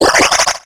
Cri de Morphéo dans Pokémon X et Y.